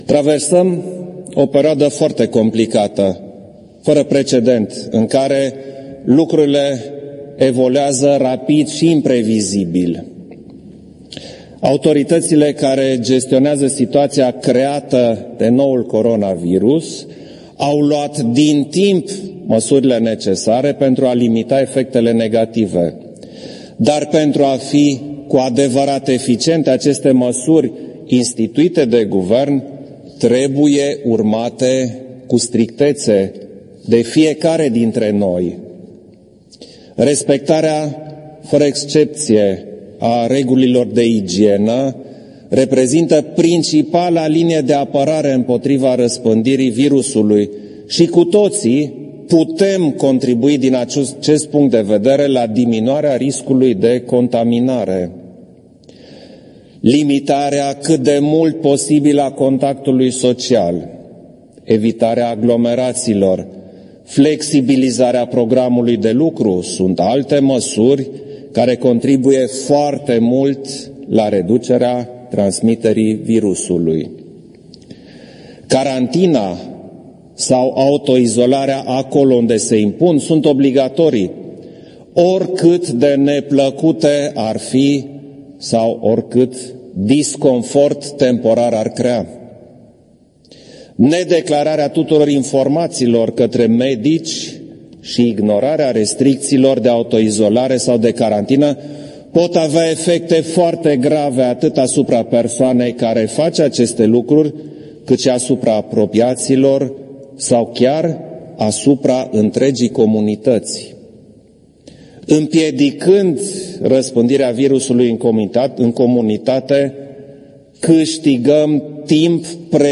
Într-un discurs susținut la Palatul Cotroceni, în care a anunțat că a convocat partidele la consultări, vineri, privind desemnarea unui nou premier, președintele Klaus Iohannis a transmis câteva recomandări românilor în contextul măsurilor privind coronavirusul.
În același discurs susținut joi seară la Palatul Cotroceni, șeful statului i-a îndemnat pe români să nu se panicheze și să nu creadă ori să răspândescă știri false.